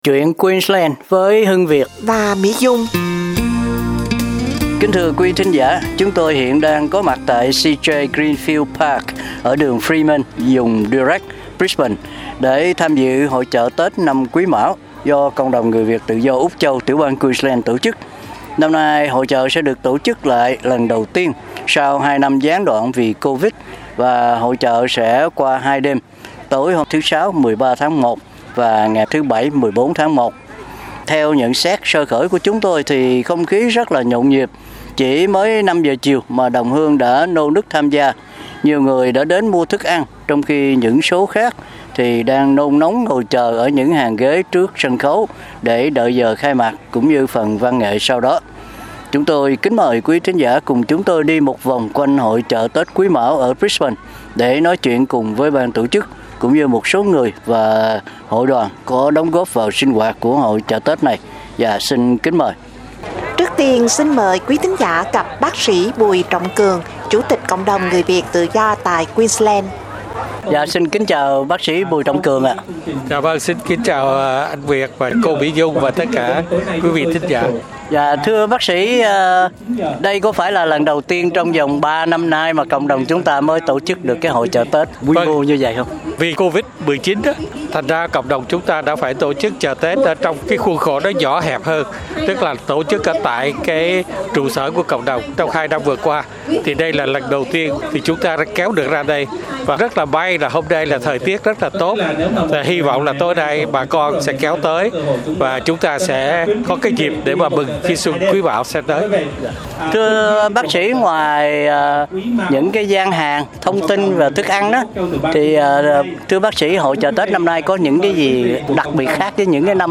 Theo nhận xét sơ khởi của chúng tôi thì không khí rất là nhộn nhịp, chỉ mới 5 giờ chiều mà đồng hương đã nô nức tham gia.